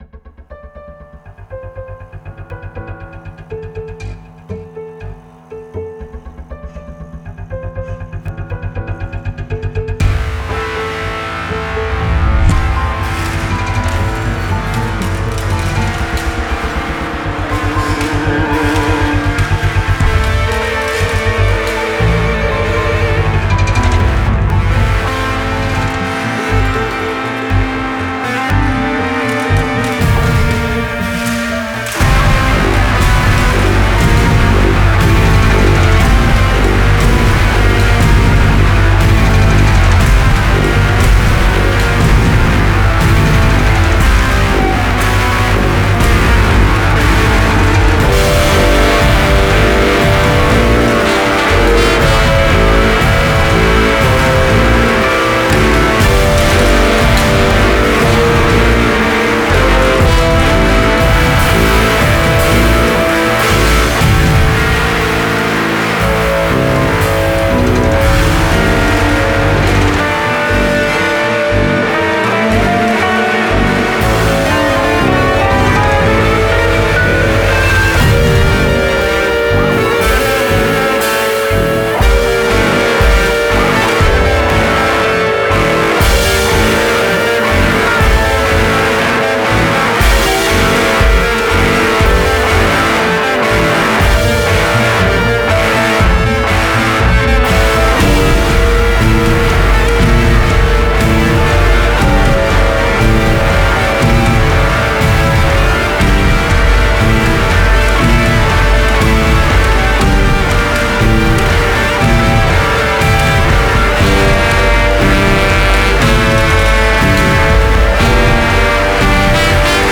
А вот тут гитарные, и что ?
(саунд да слабый, плоский) Вот представьте, идет в фильме бой, эпизод - рубилово.